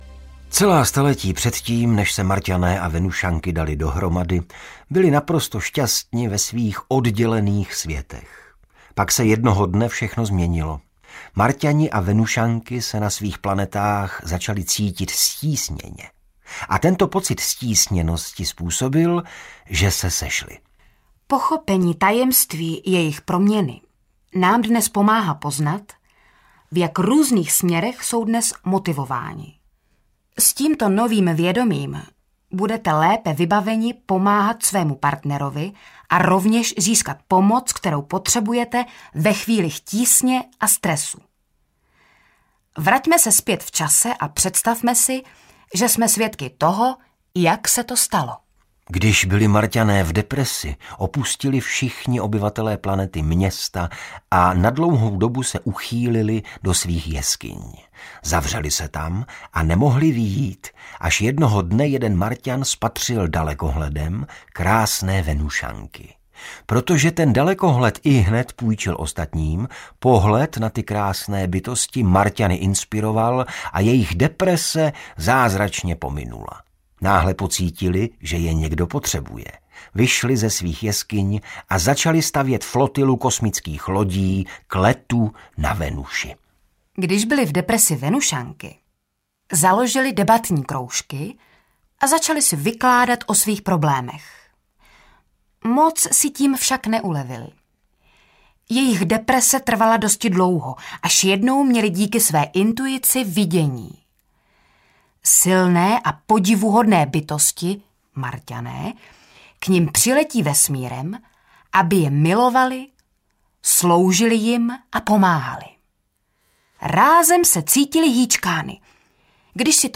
Muži jsou z Marsu, ženy z Venuše audiokniha
Ukázka z knihy